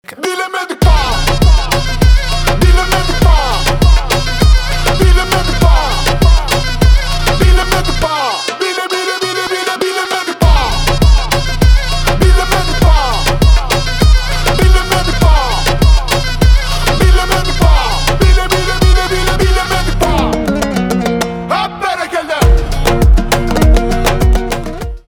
рэп
басы